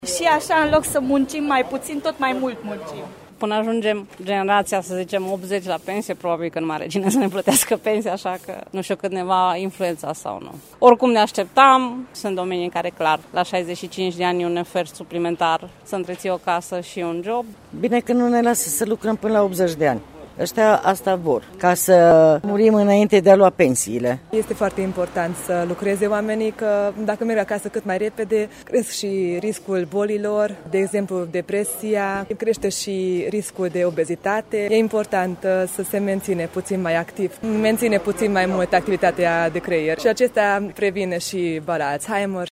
Târgmureșencele au păreri împărțite cu privire la această schimbare: